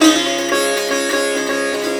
SITAR GRV 15.wav